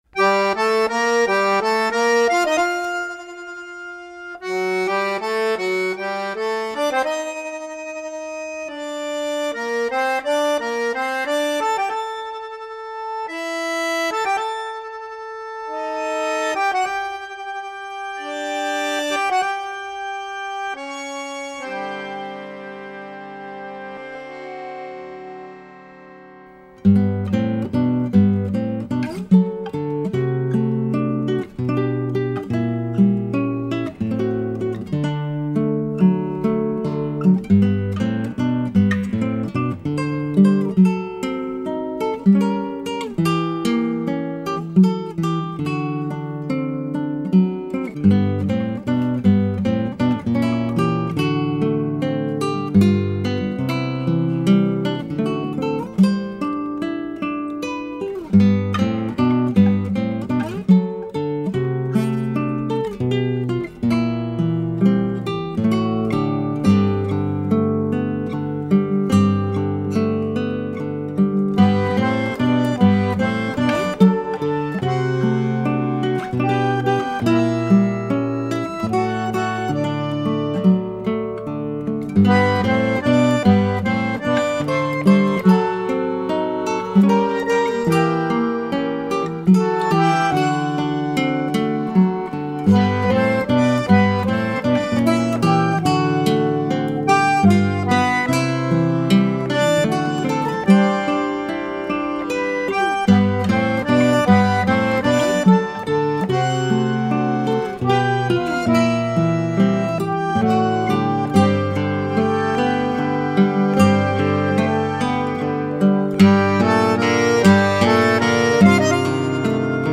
03:44:00   Baião